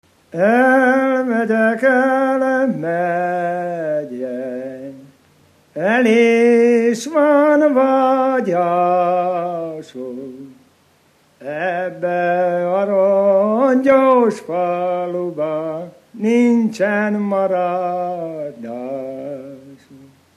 Felföld - Heves vm. - Szuha
ének
Stílus: 6. Duda-kanász mulattató stílus
Szótagszám: 6.6.8.6
Kadencia: 1 (1) b3 1